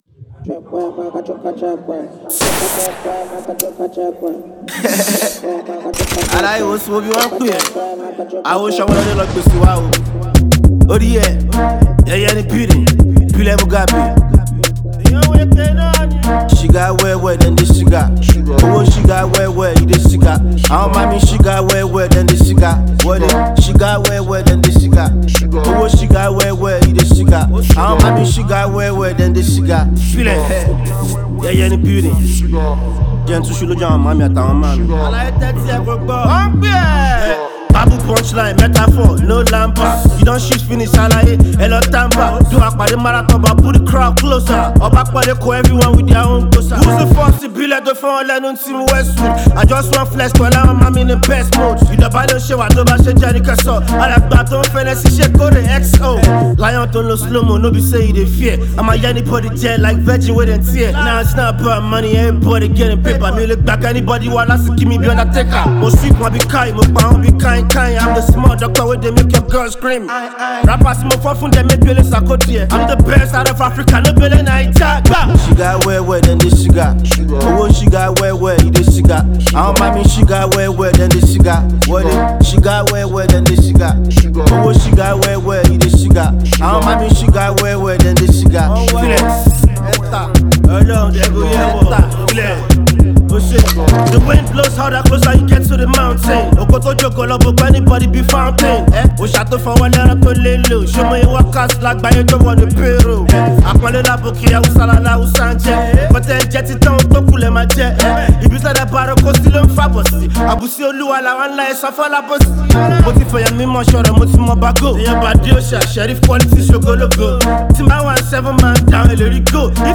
hip-hop number